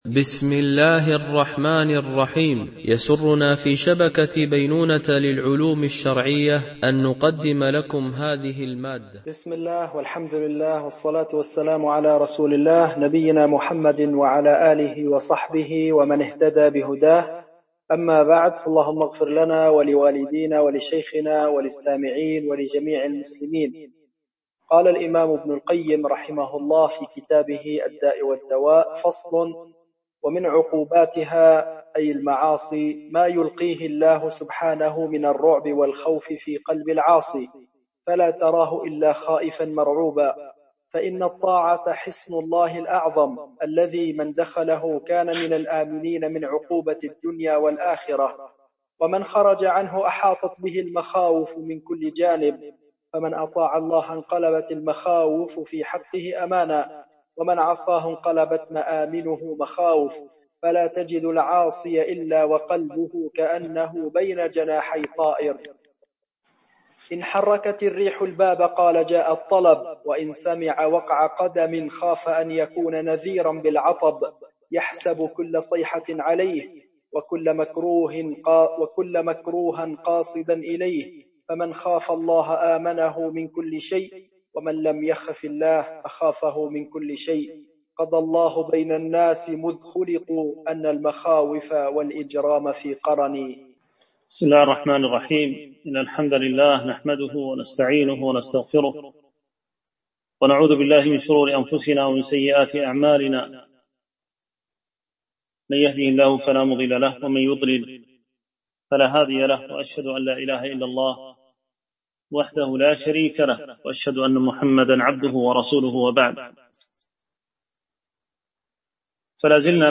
شرح كتاب الداء والدواء ـ الدرس 18